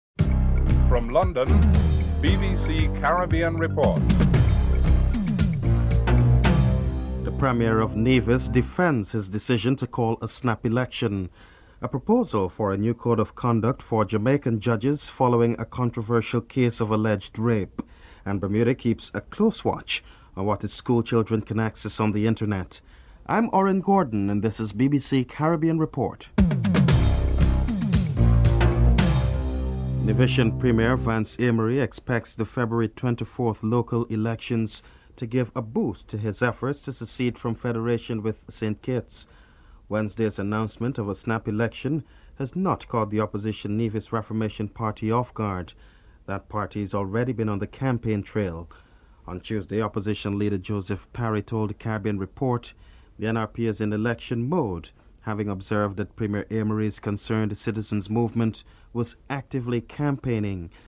1. Headlines (00:00-00:29)
Premier of Nevis, Vance Amory is interviewed (00:30:03:41)